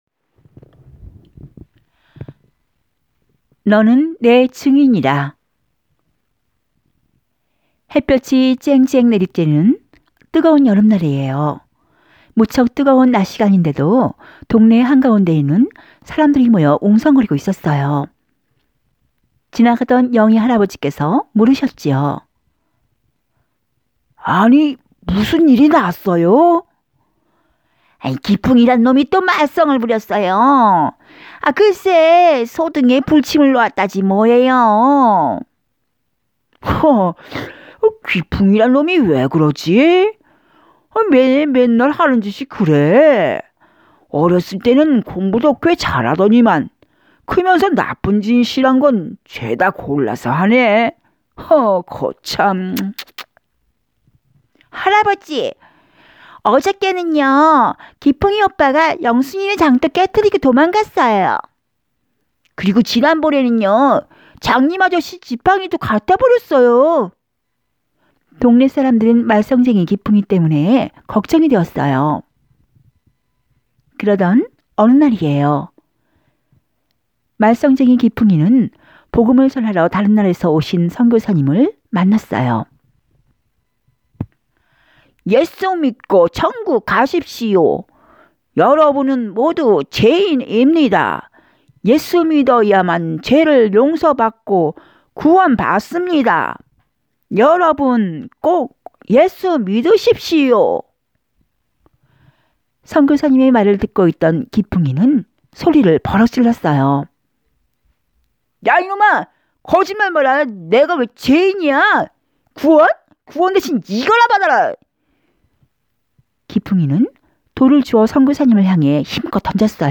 동화구연가>